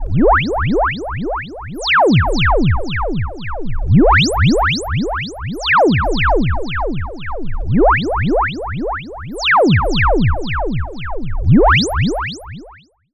Echo Bleeps.wav